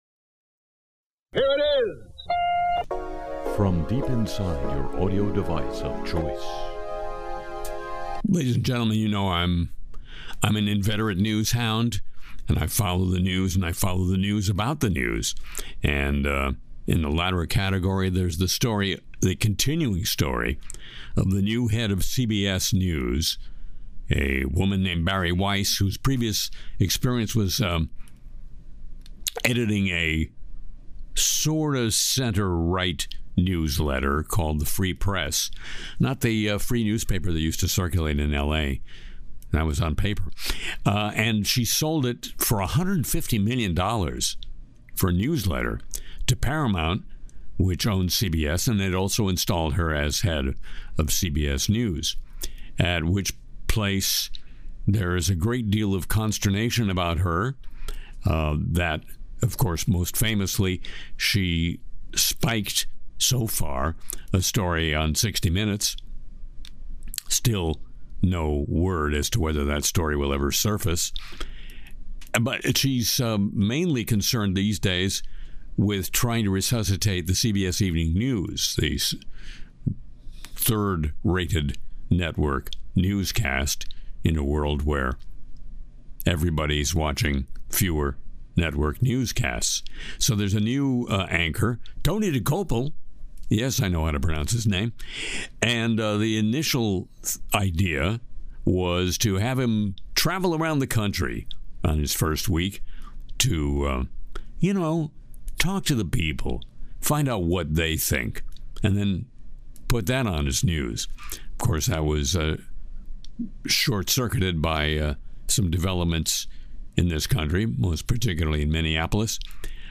Harry Shearer sings “American Guys on Mars,” skewers TV anchors in “Breaking News, Tonight,” mocks Trump’s Truth Social Audio, and digs into AI bans, biometrics, and Musk drama.